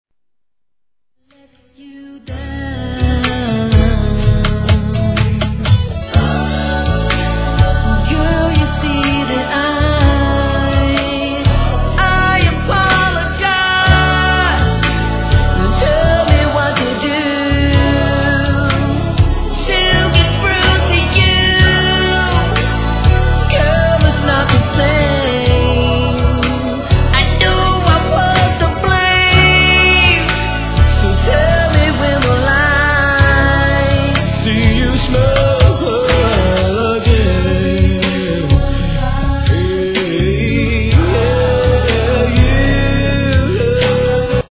HIP HOP/R&B
透き通るようなコーラスと語りがよりバラード感を引き立たせた'91年R&B!!!